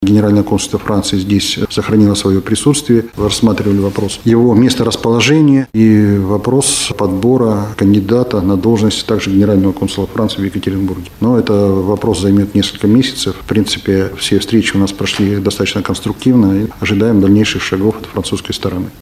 С ним обсуждали вопрос работы генконсульства в городе, — рассказал представитель МИД России в Екатеринбурге Александр Харлов на пресс-конференции «ТАСС-Урал».